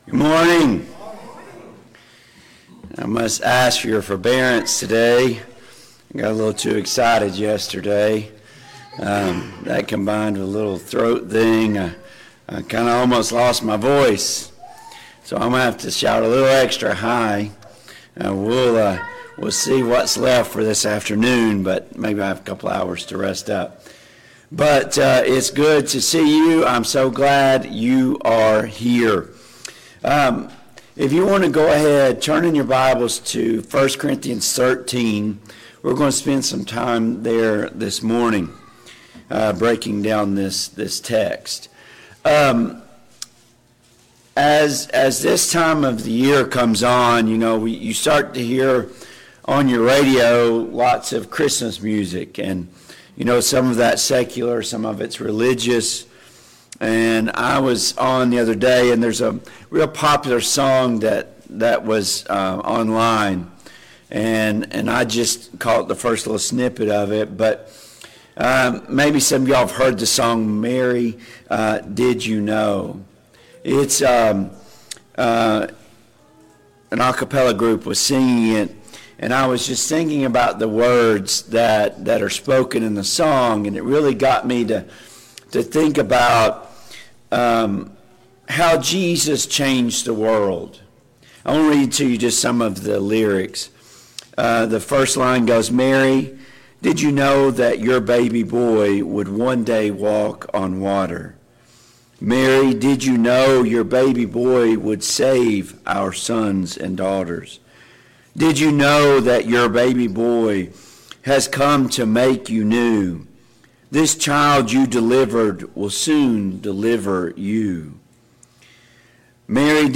Service Type: AM Worship Topics: Christian Love , Unity